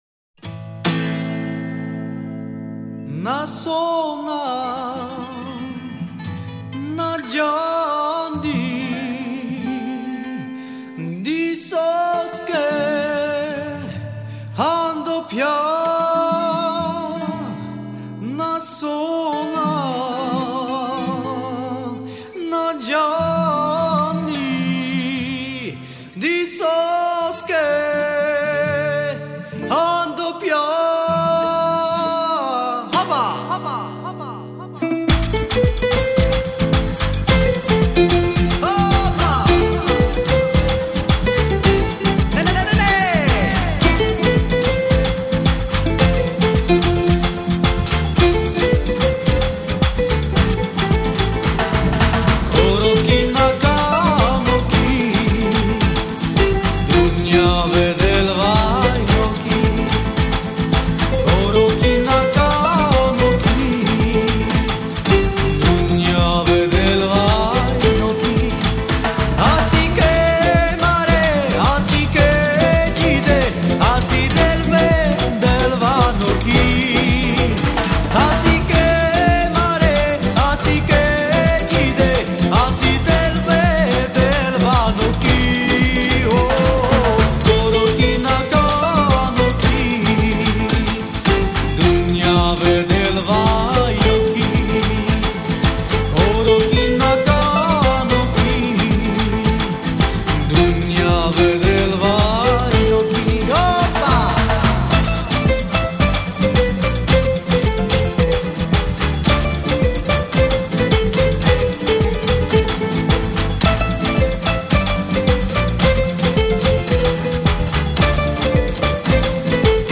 Цыганская версия